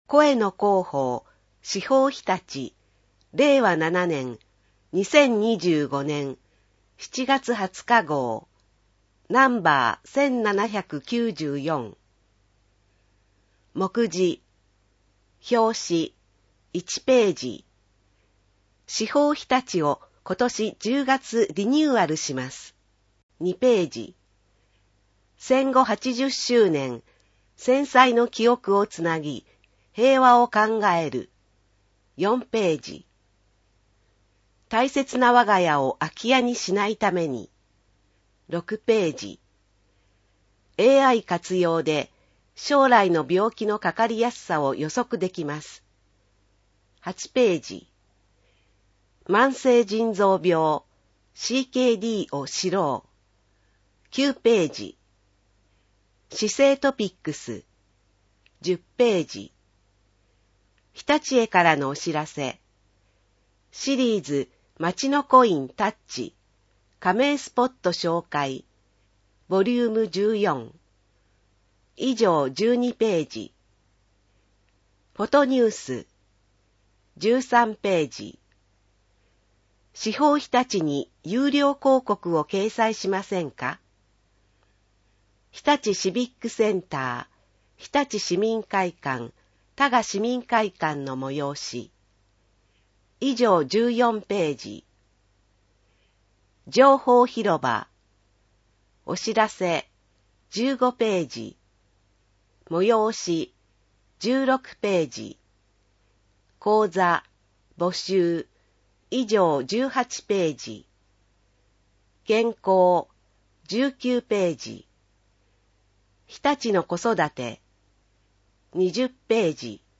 声の市報を読みあげます。